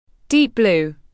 Deep blue /diːp bluː/